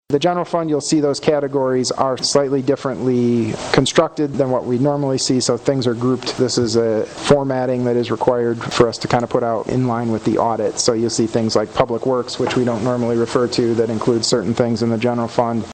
Wednesday night, the Sturgis City Commission held a public hearing on the budget during their regular meeting. Sturgis City Manager Andrew Kuk shared that the format is a little different than previous budgets.